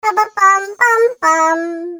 • Качество: 320, Stereo
забавные
милые
детский голос
Детский лепет на уведомления